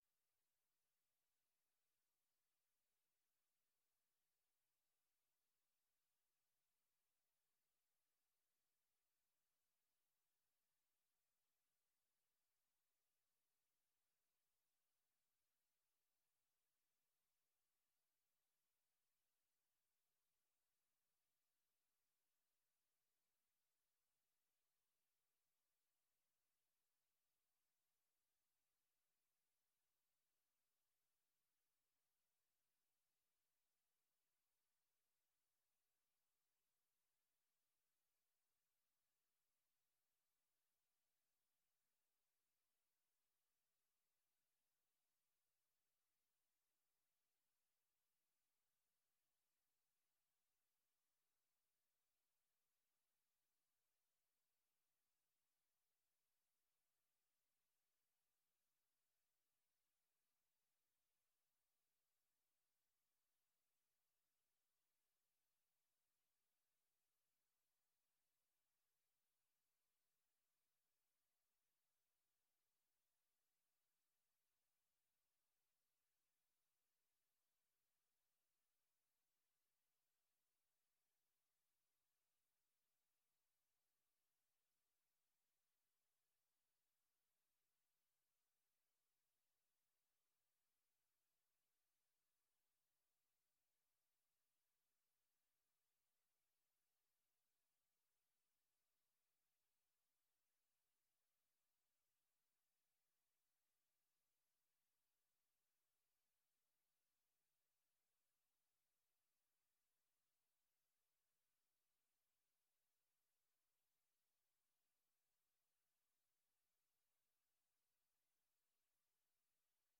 அதனால் பிபிசி தமிழோசையின் முதல் 12 நிமிடநேர ஒலிபரப்பு சிற்றலை வானொலி மூலமாகவும், பிபிசி தமிழின் இணையதளத்தில் நேரலையிலும் ஒலிபரப்பாகவில்லை. இந்த தொழில்நுட்பக்கோளாறு 5 மணிக்கு சரிசெய்யப்பட்டபிறகு பிபிசி தமிழின் மீதமுள்ள நிகழ்ச்சி மட்டுமே வானொலியிலும், இணையத்திலும் பதிவானது.